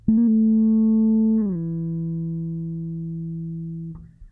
bass7.wav